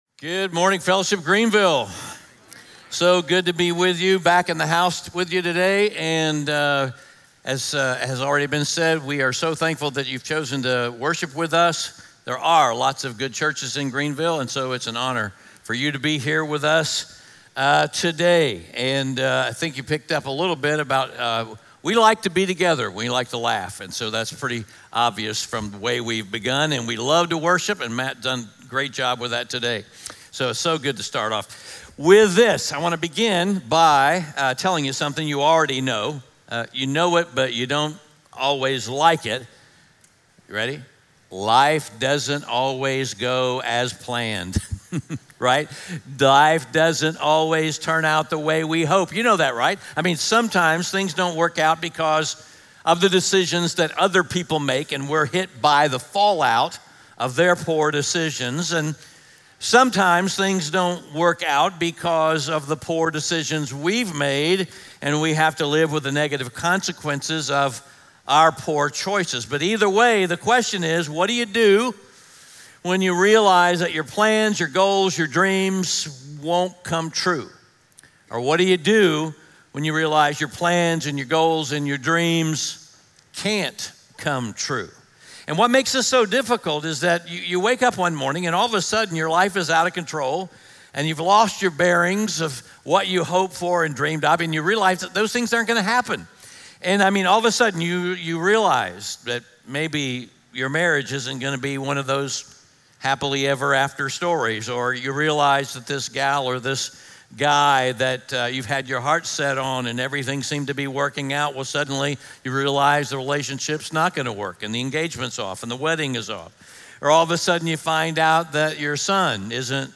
SERMON SCREENSHOTS & KEY POINTS